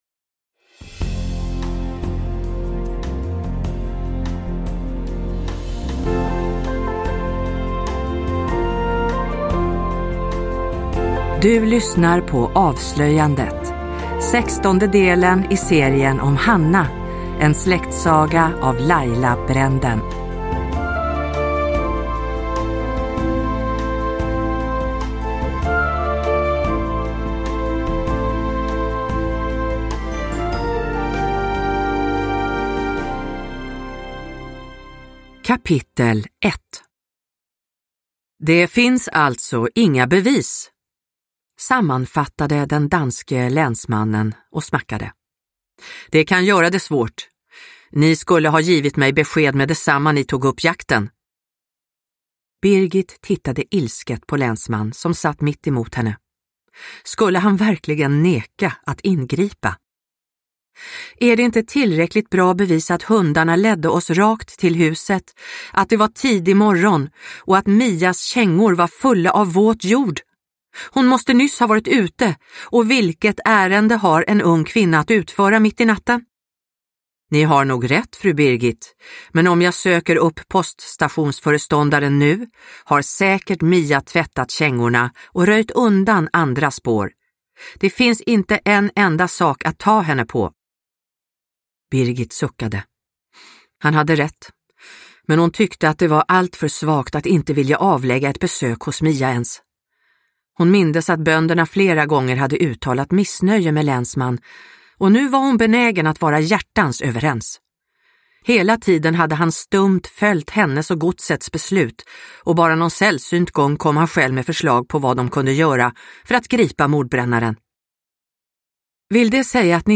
Avslöjandet – Ljudbok